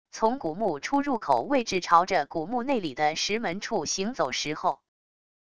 从古墓出入口位置朝着古墓内里的石门处行走时候wav音频